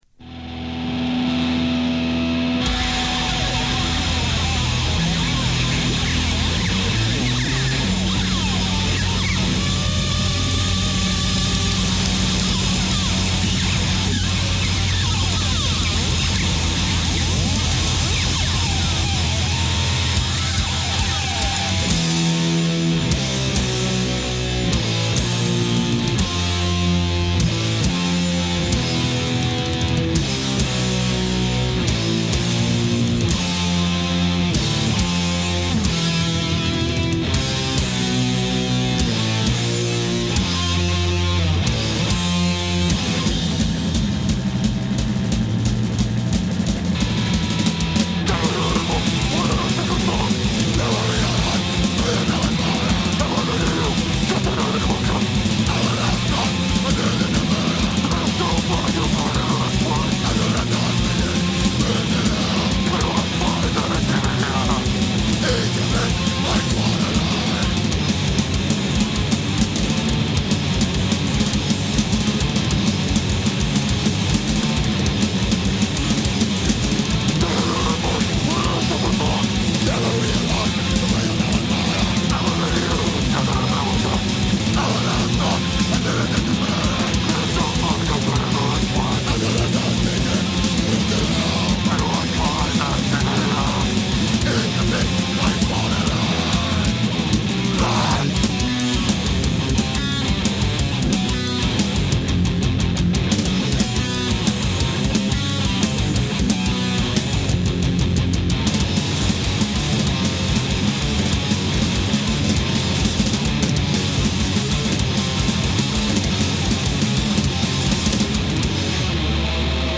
hardcore trio